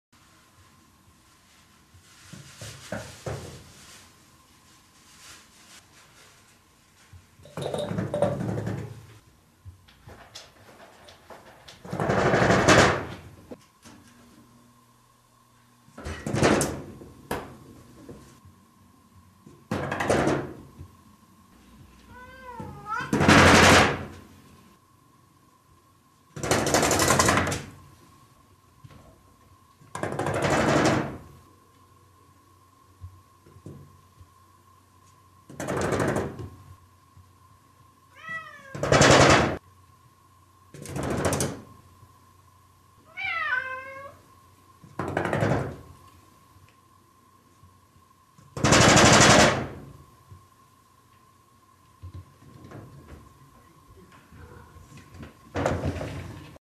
Звук кошачьих лап шлепающих по стене